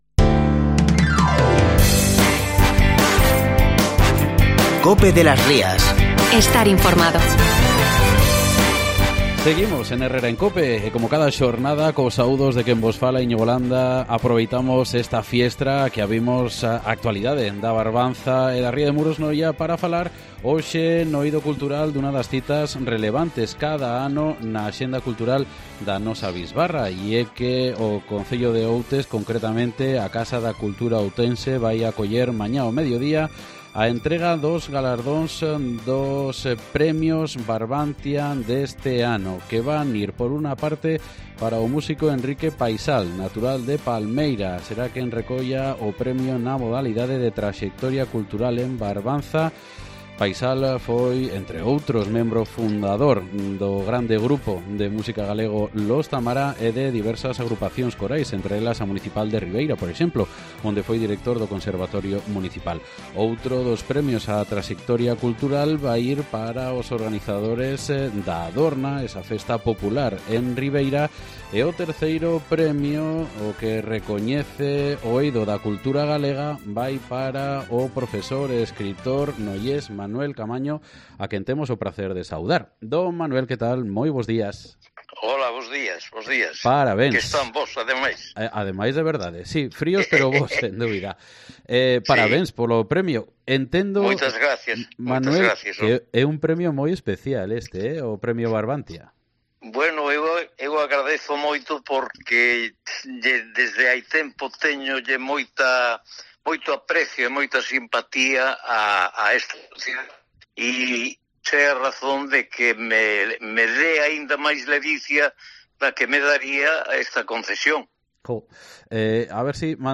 Escucha la entrevista en COPE de las Rías